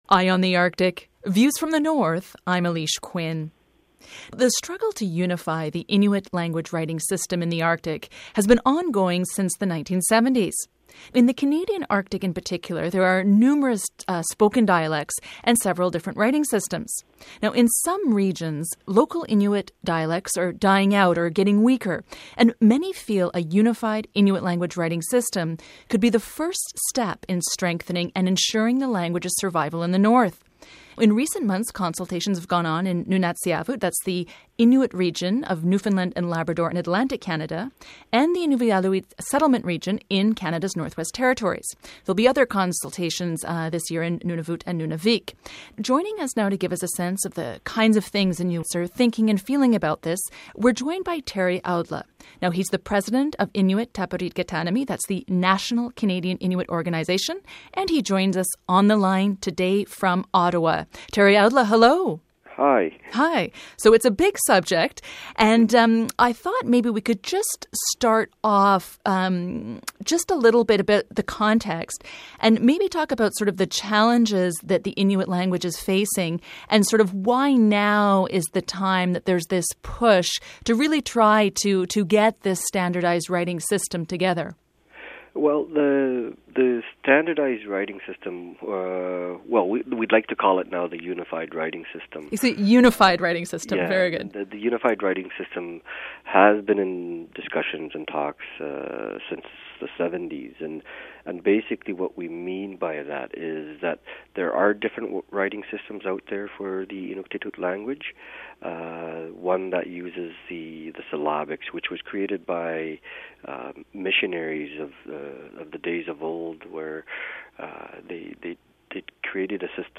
And in our Friday Feature Interview this week, we take a look at language.